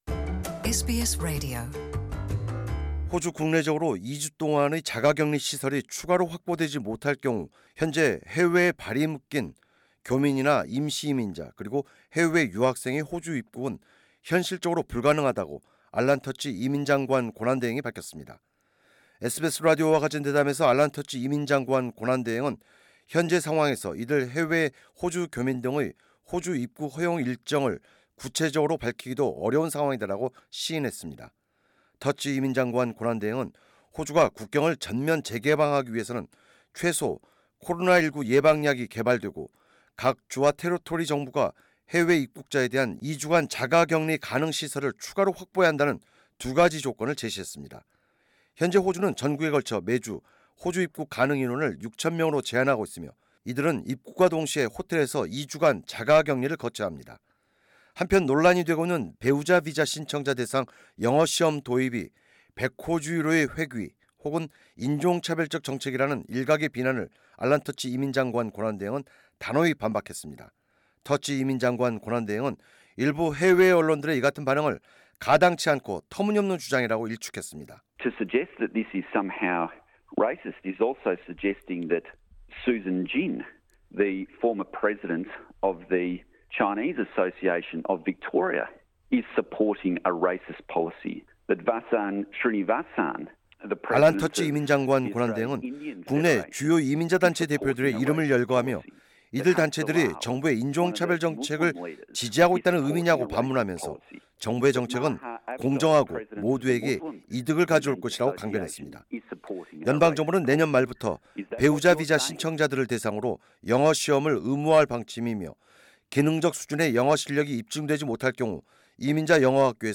In an exclusive interview with SBS Punjabi, Acting Immigration Minister Alan Tudge has said that unless more quarantine spaces are made available, Australian citizens, temporary migrants and international students stuck overseas due to COVID-19 pandemic won't be able to return to Australia. And he rejects that the introduction of an English language test for partner visa applicants is 'racist', 'discriminatory', or a new version of the White Australia policy.